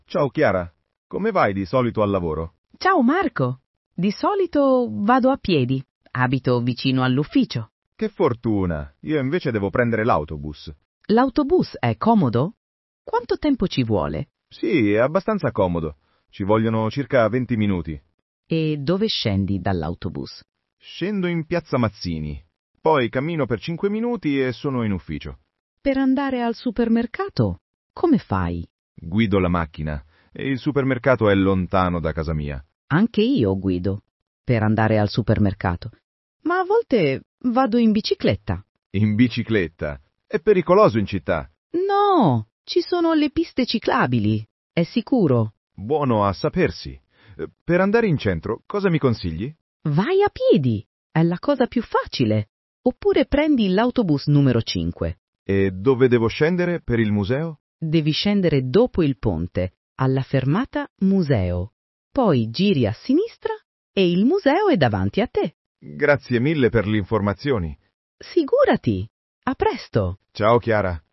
Listen to the conversation, then answer the question: Choose the correct answer: Next lesson: Qual è la tua stanza preferita? / What’s your favorite room?